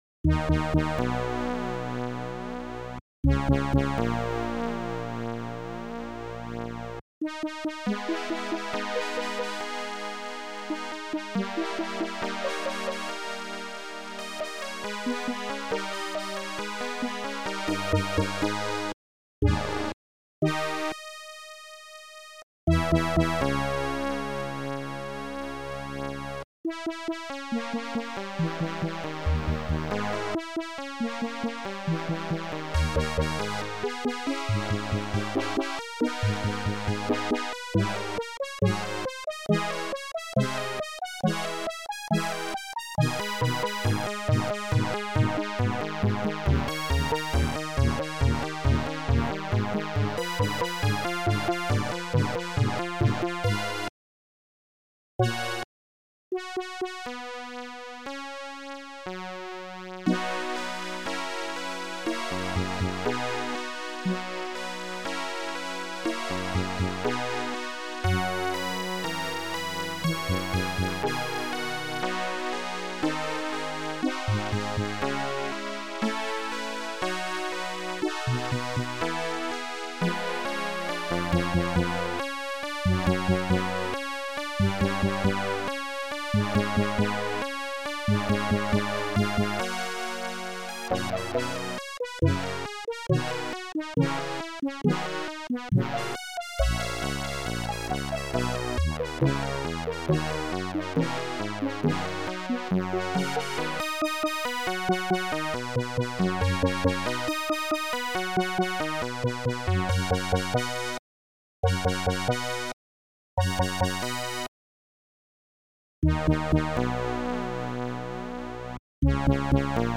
Creative Music Format
Beethoven - Symphony #5 In C Minor Op. 67